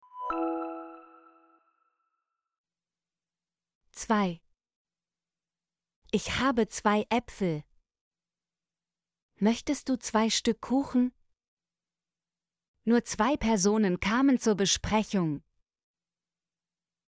zwei - Example Sentences & Pronunciation, German Frequency List